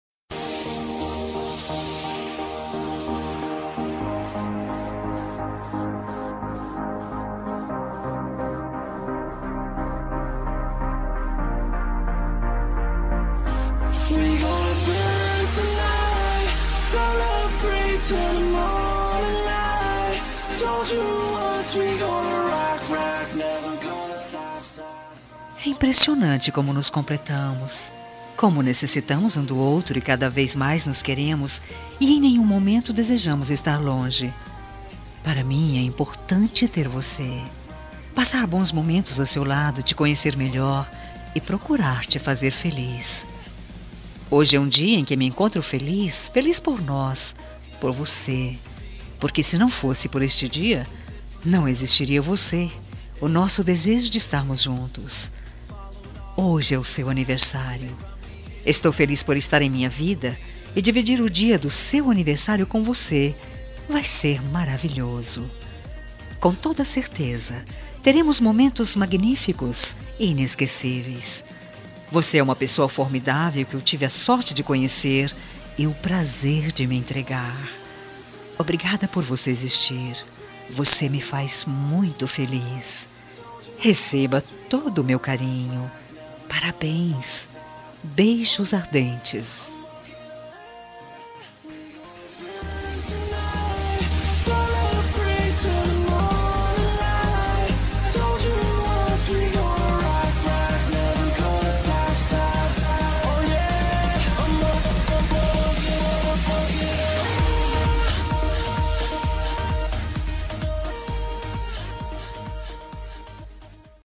Telemensagem de Aniversário Romântico – Voz Feminina – Cód: 202124 – Amante